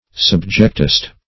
Search Result for " subjectist" : The Collaborative International Dictionary of English v.0.48: Subjectist \Sub"ject*ist\, n. (Metaph.)
subjectist.mp3